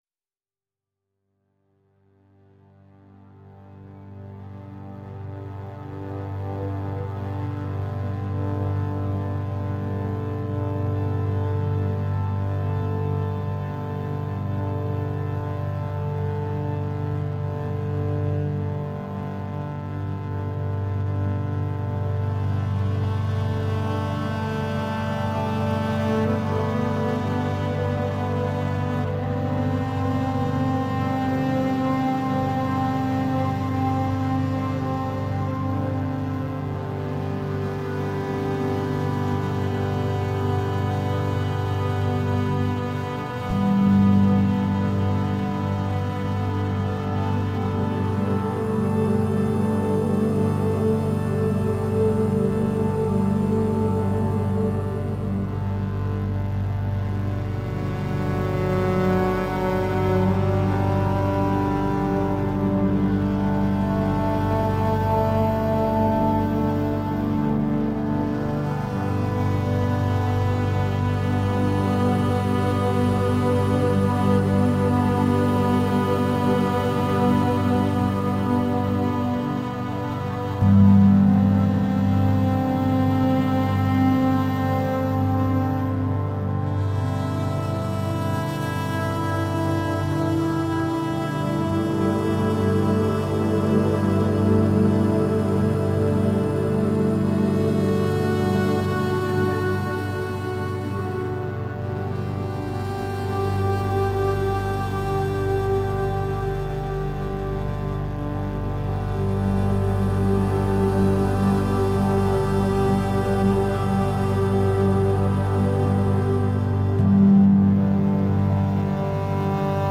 Enchanting cello compositions.
This is powerful musical medicine.
Tagged as: New Age, Ambient, Cello, Ethereal, Massage